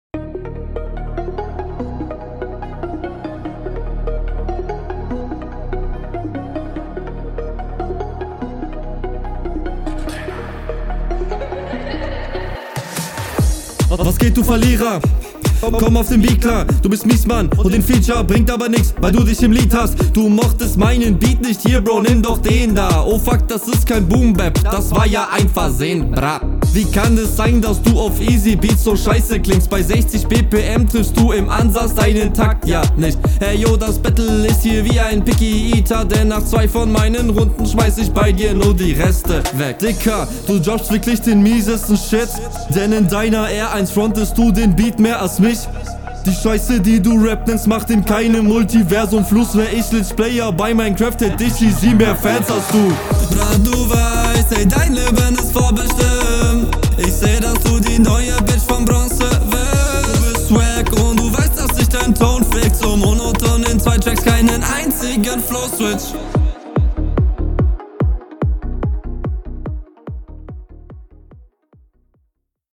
der style steht dir tatsächlich krass. hört sich einfach dope an.
Cooler Beat